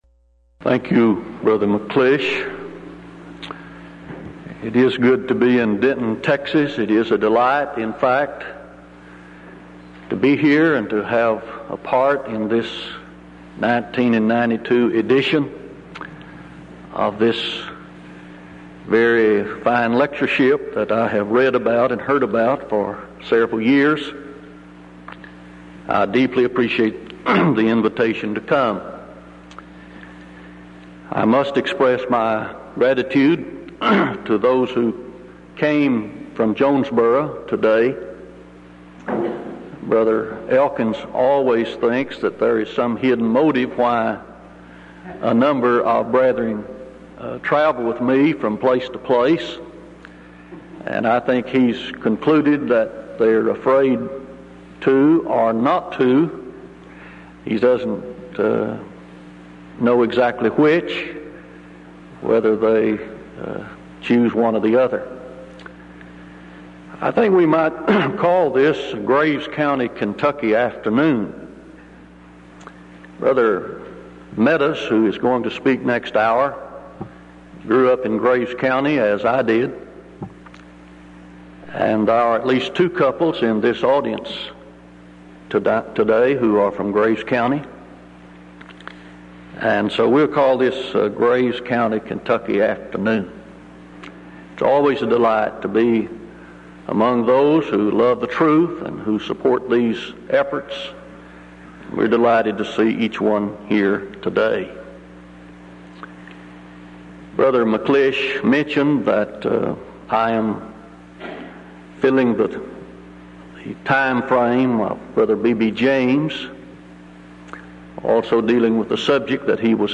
Event: 1992 Denton Lectures Theme/Title: Studies In Ezra, Nehemiah And Esther